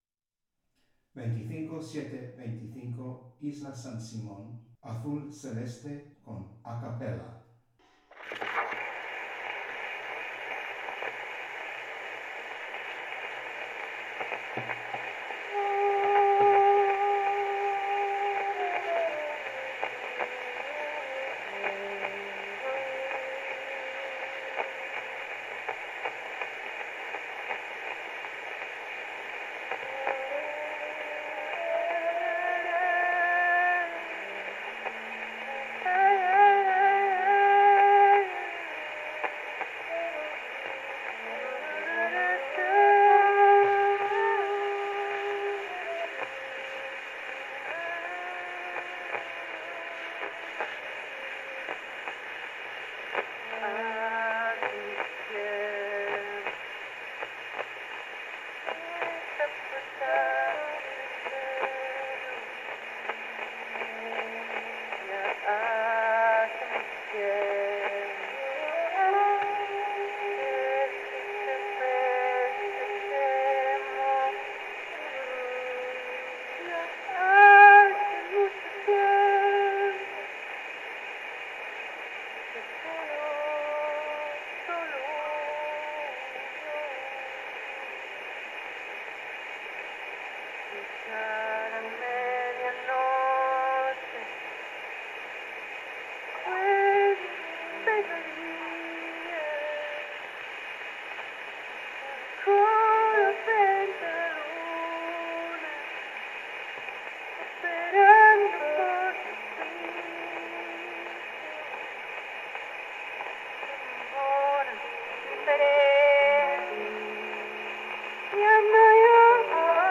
Original sound from the phonographic cylinder.